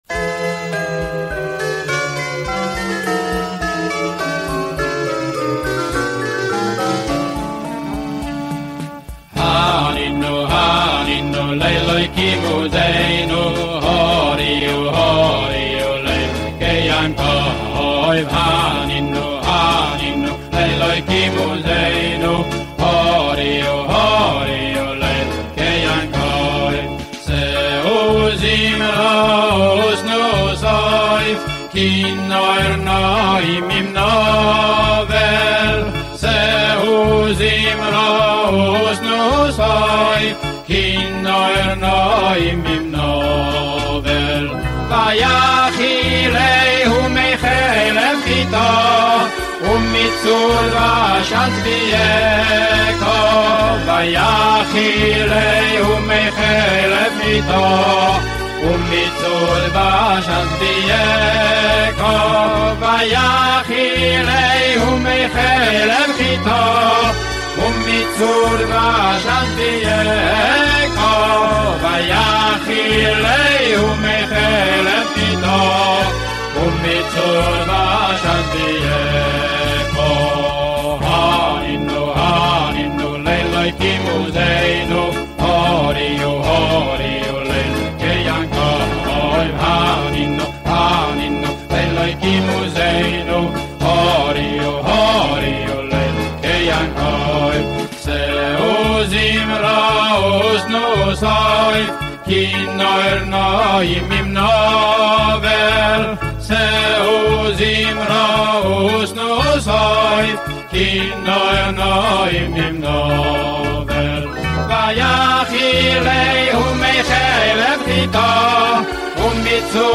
ניגון חסידי